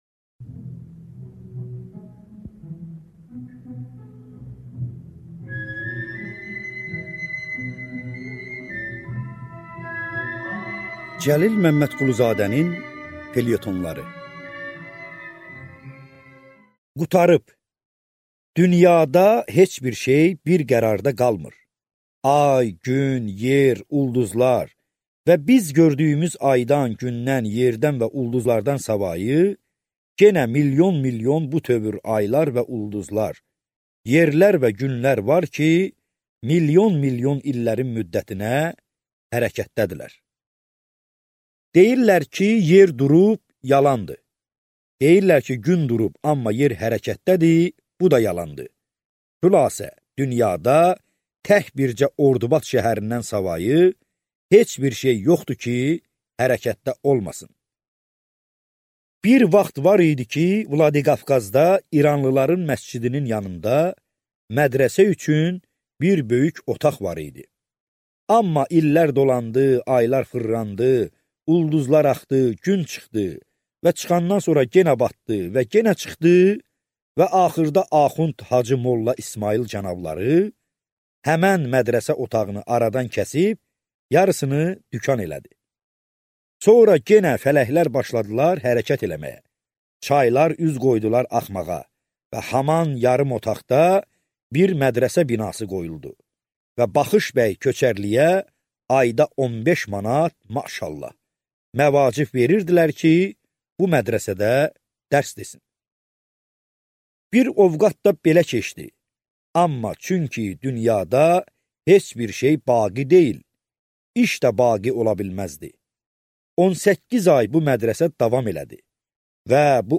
Аудиокнига Felyetonlar II kitab (ardı) | Библиотека аудиокниг